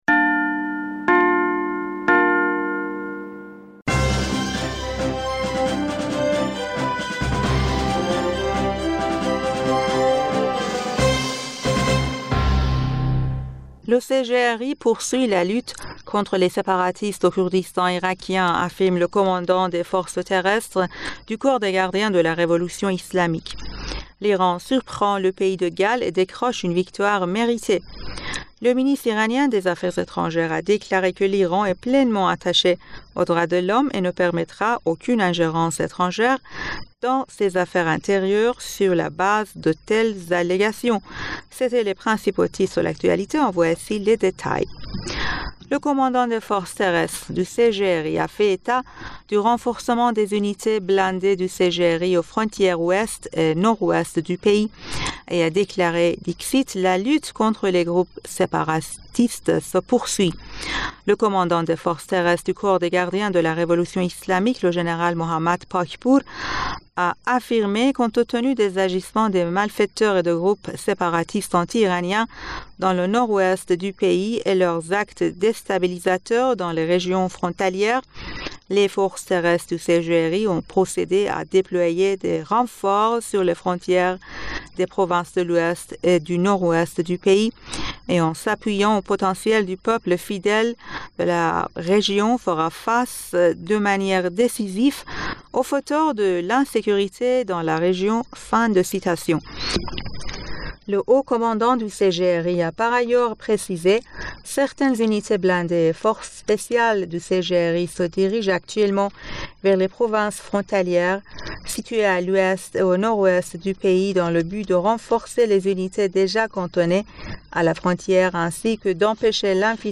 Bulletin d'information du 25 Novembre